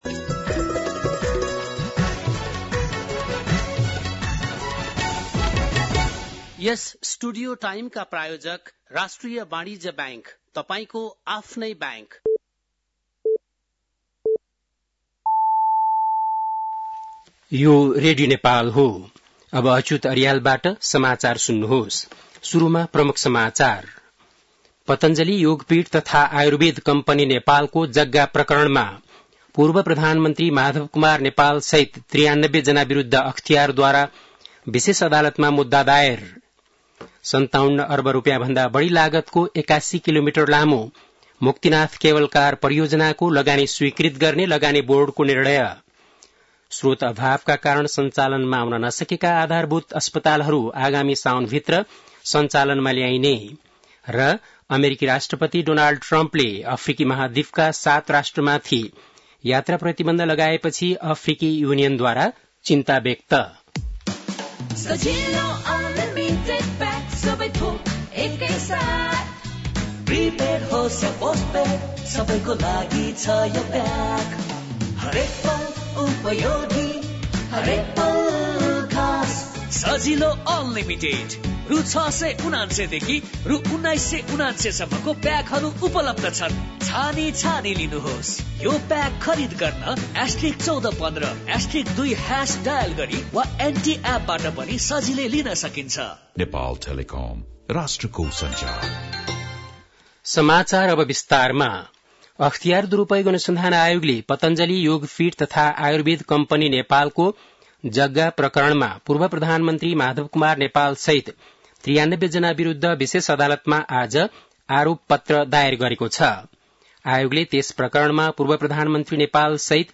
बेलुकी ७ बजेको नेपाली समाचार : २२ जेठ , २०८२
7.pm-nepali-news-2-22.mp3